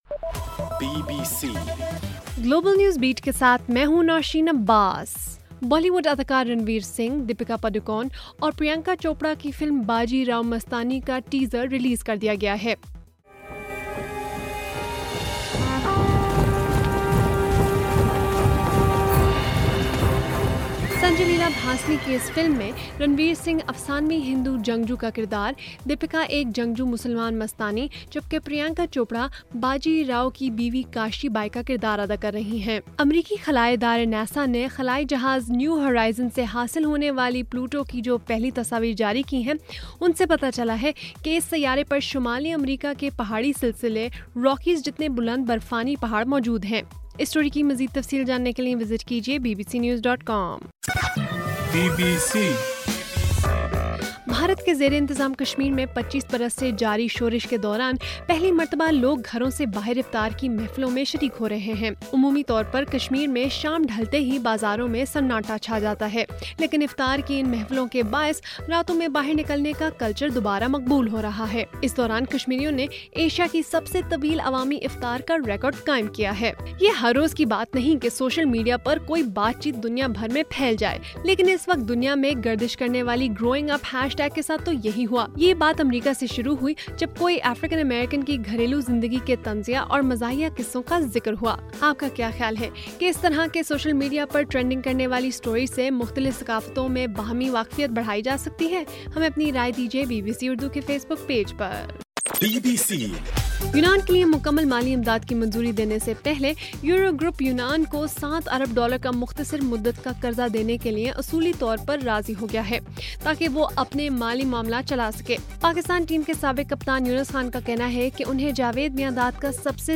جولائی 16: رات 11 بجے کا گلوبل نیوز بیٹ بُلیٹن